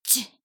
大人女性│女魔導師│リアクションボイス│商用利用可 フリーボイス素材 - freevoice4creators
怒る